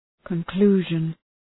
Προφορά
{kən’klu:ʒən}